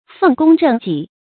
奉公正己 fèng gōng zhèng jǐ
奉公正己发音